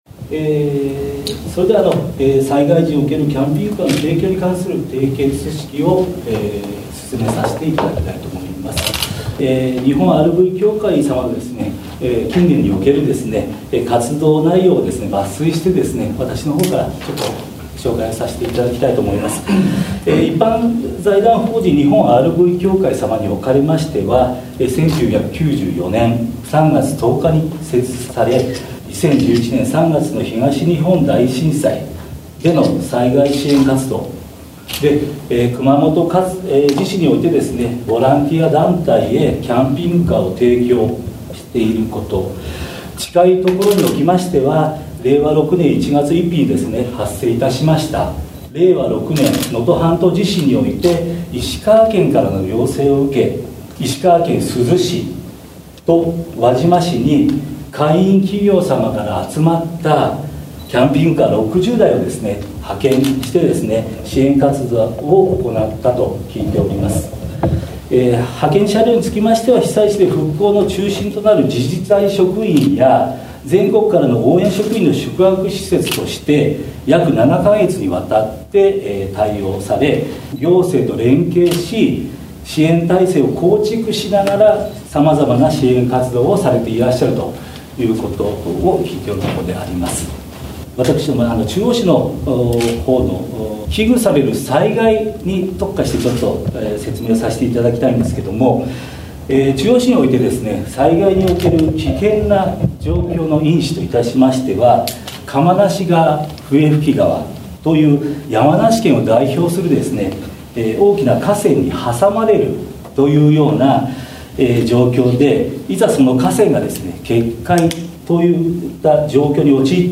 11月7日に締結式が中央市役所で行われ、番組では、その模様を紹介しました。